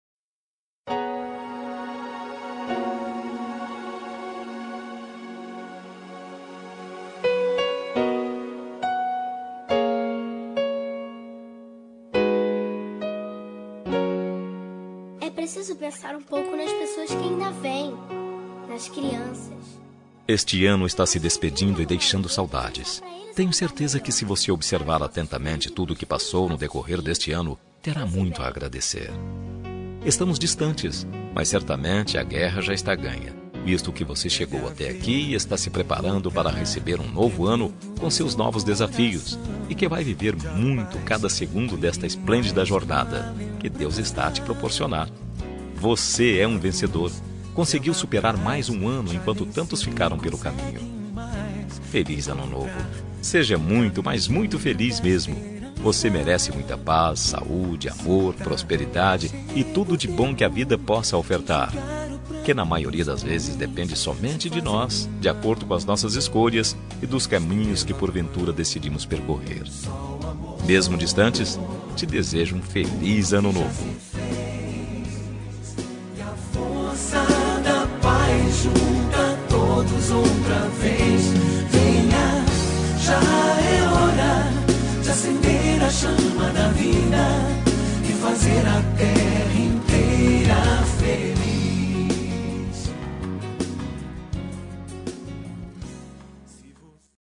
Ano Novo – Distante – Voz Masculina – Cód: 6431